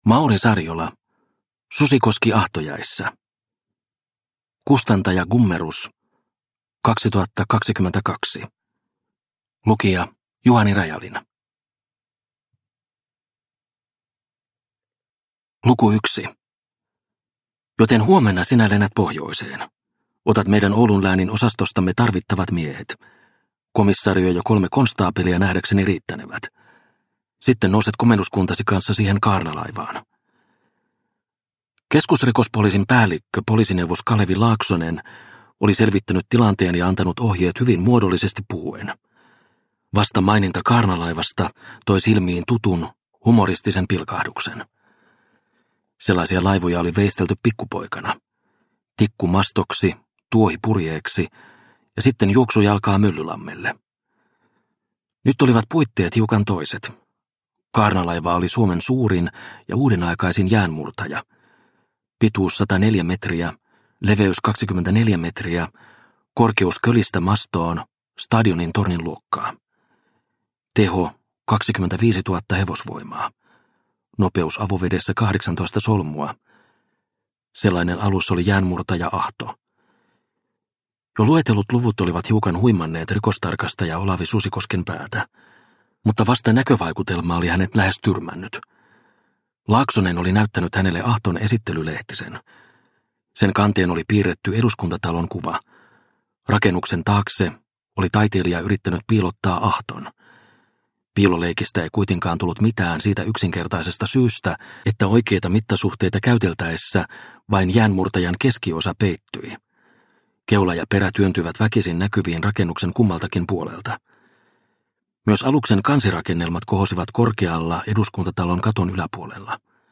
Susikoski ahtojäissä – Ljudbok – Laddas ner